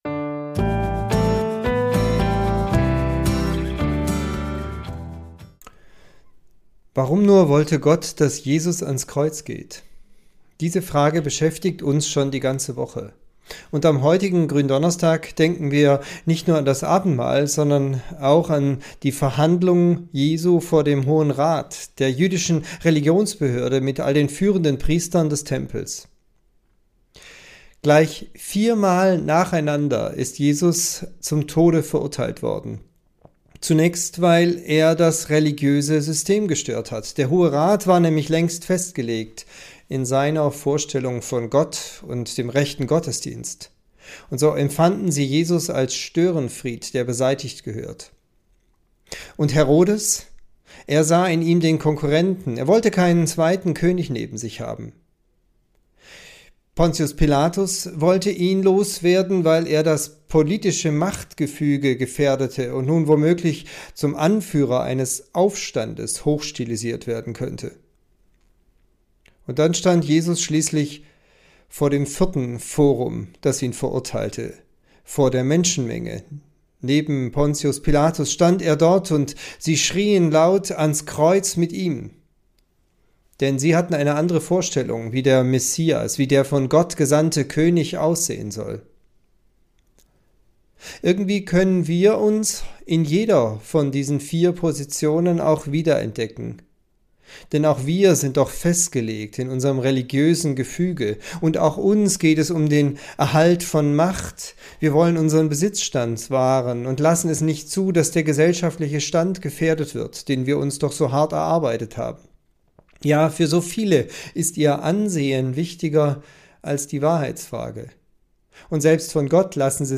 Tübinger Telefonandacht zur Tageslosung